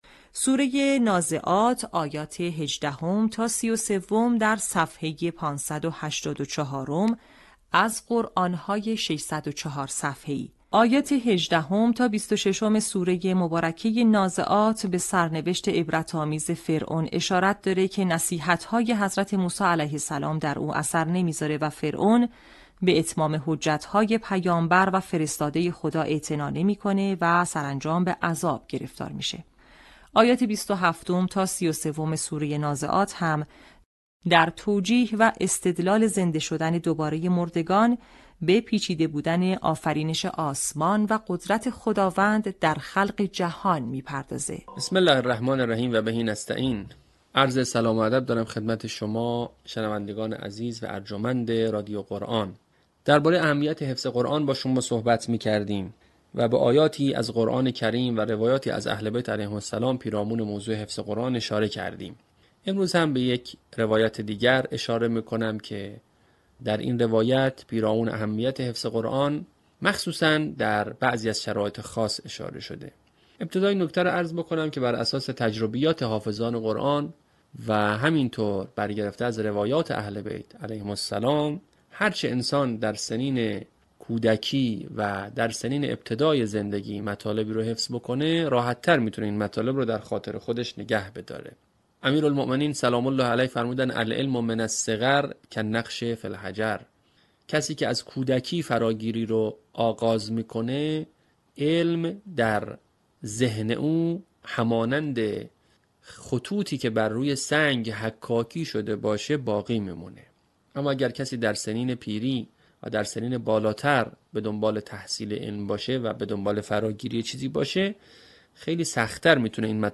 به همین منظور مجموعه آموزشی شنیداری (صوتی) قرآنی را گردآوری و برای علاقه‌مندان بازنشر می‌کند.
آموزش حفظ جزء ۳۰، آیات ۱۸ تا ۳۳ سوره نازعات